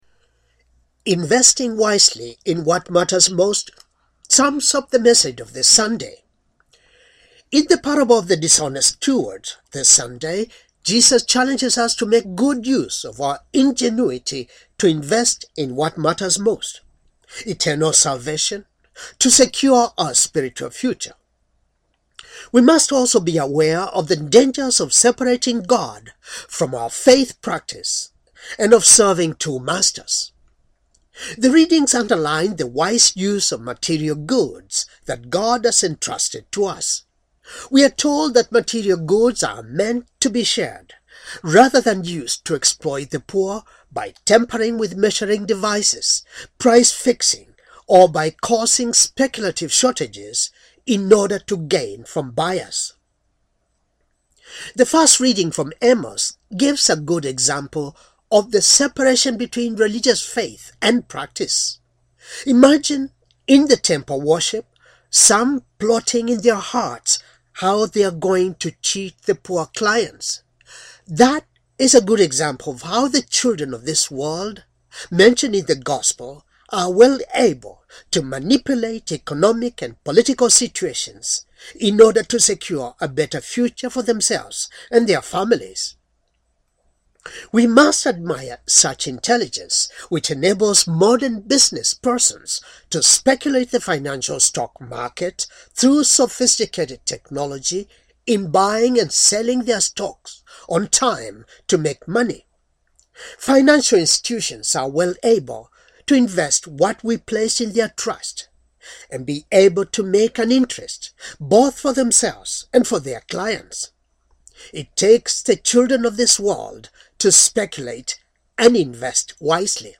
Homily for Twenty Fifth Sunday, Ordinary Time, Year C